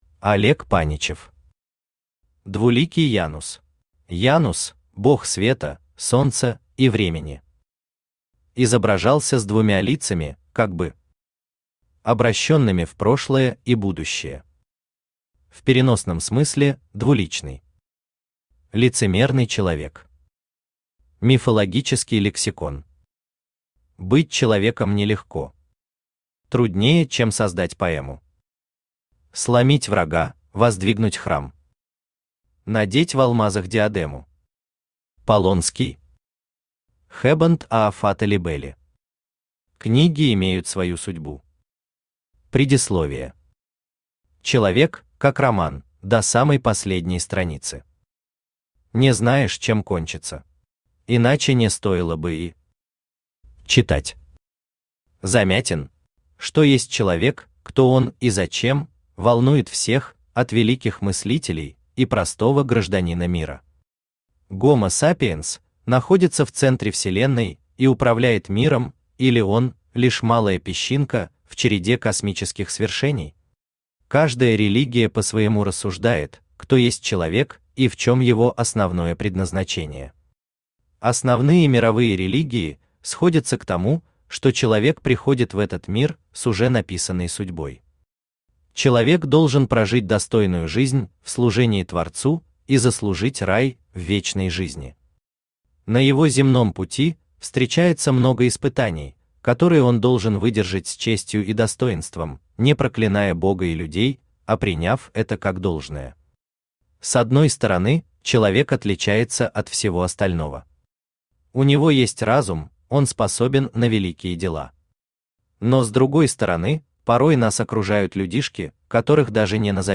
Аудиокнига Двуликий Янус | Библиотека аудиокниг
Aудиокнига Двуликий Янус Автор Олег Владимирович Паничев Читает аудиокнигу Авточтец ЛитРес.